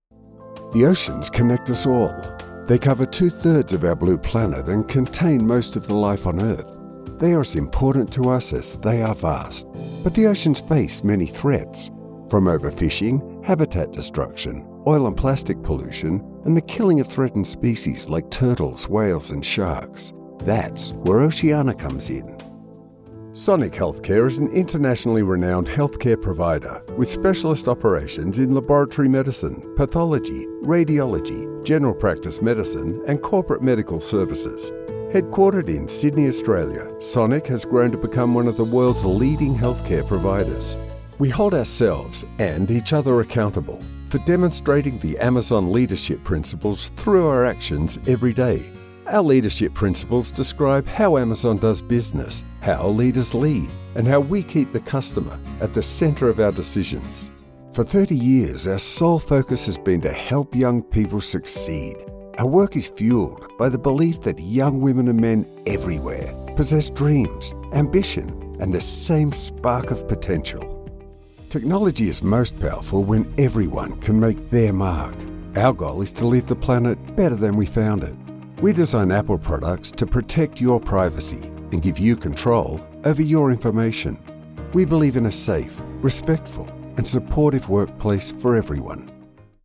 I perform recordings daily in my specialized, top-tier voiceover studio.
Voiceover Demos
Corporate Demo